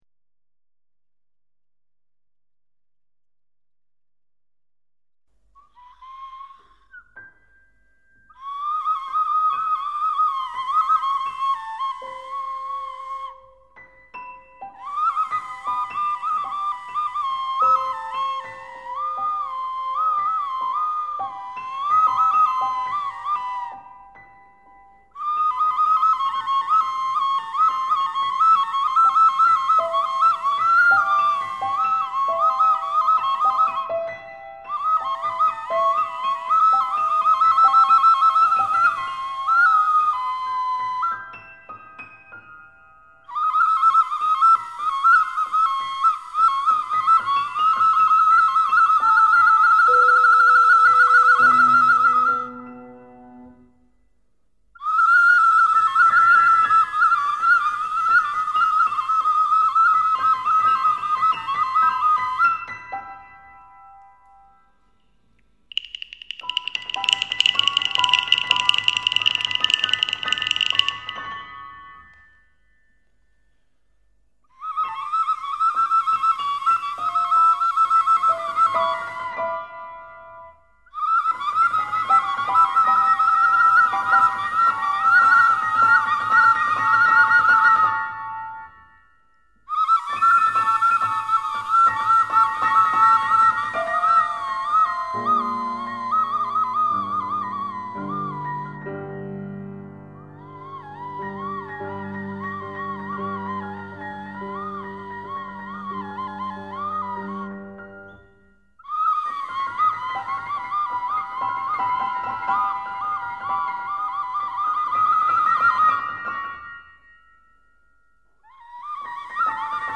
Coreography for a Ballet
For two pianos in abstract coupling
2° piano* (coupling); 1° piano*** (coupled) : with Schönberg